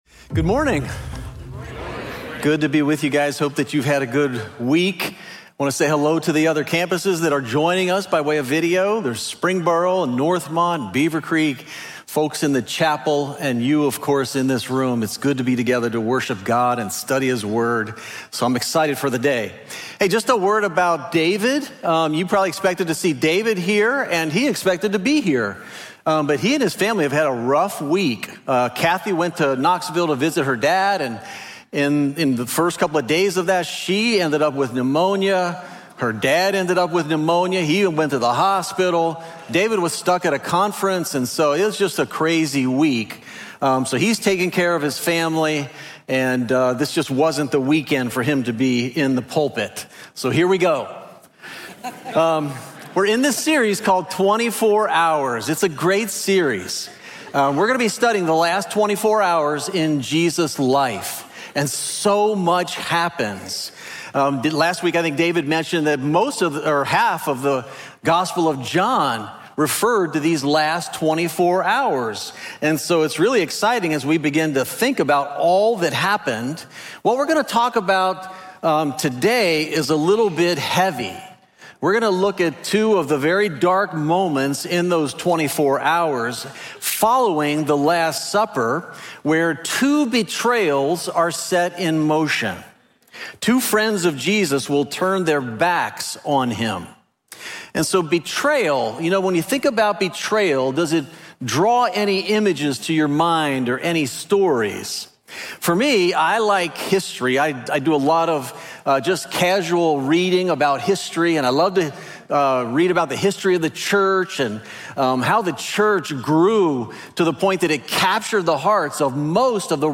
24-Hours_When-Someone-You-Trust-Lets-You-Down_SERMON.mp3